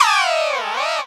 Cri de Dodoala dans Pokémon Soleil et Lune.